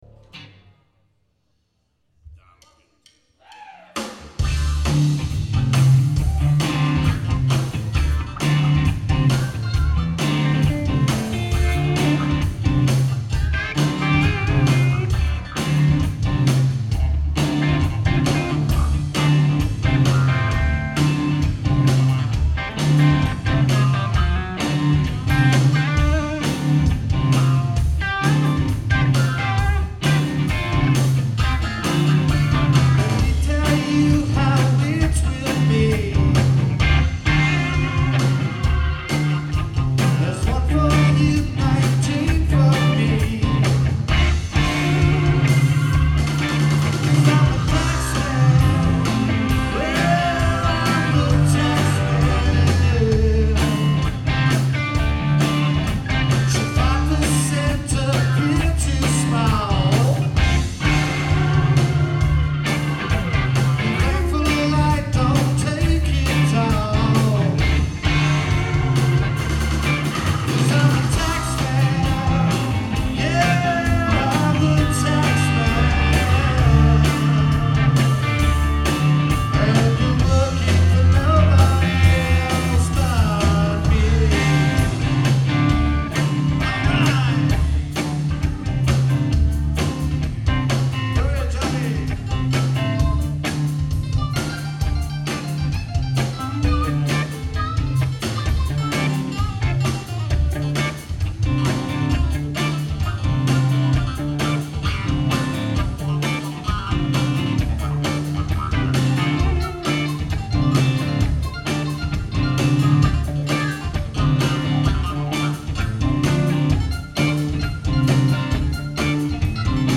very lively version